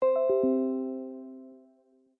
feat(shell): use windows 10 notification sound as bell
win10notify.mp3